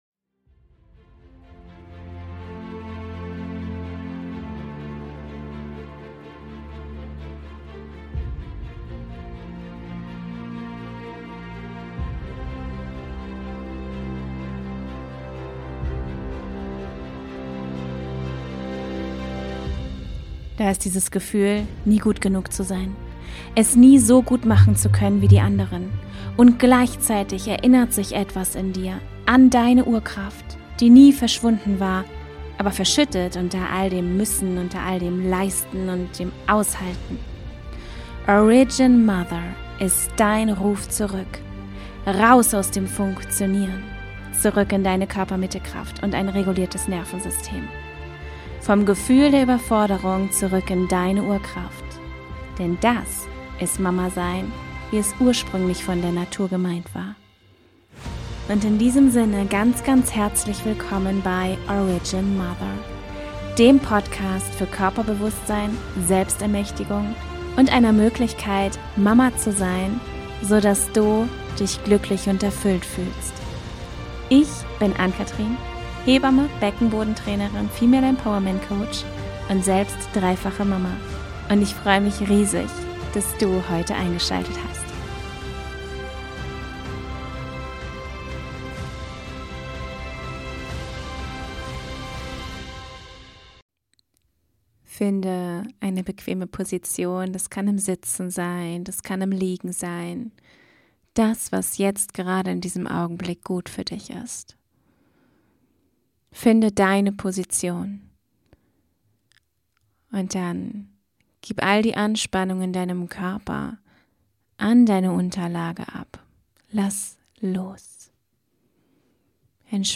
Wenn du gerade in einer erneuten Übergangsphase warst, dann ist das hier dein Raum für Reflexion und Rückblick. Eine Meditation um dich zu stärken und zu stützen und dich leichter in der neuen Version von dir wiederzufinden.